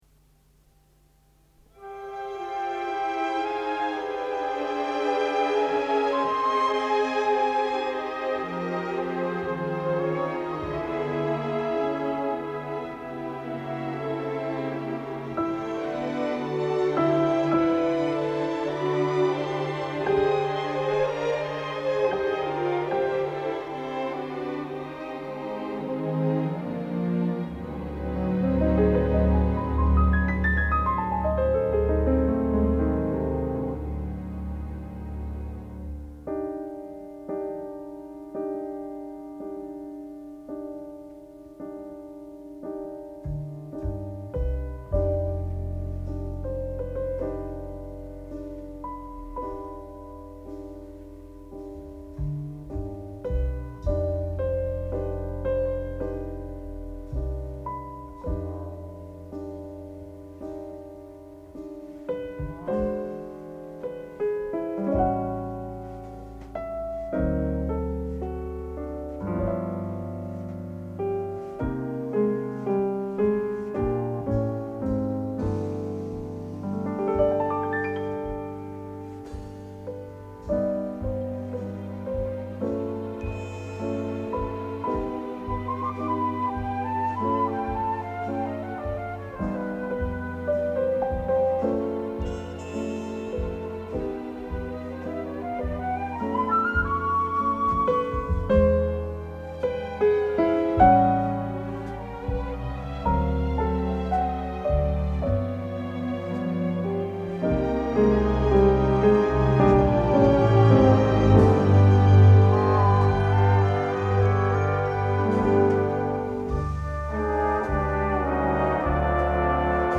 piano soloist